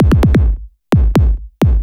Kick 130-BPM.wav